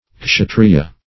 kshatriya - definition of kshatriya - synonyms, pronunciation, spelling from Free Dictionary
Kshatriya \Ksha"tri*ya\ (ksh[.a]"tr[-e]*y[.a]), Kshatruya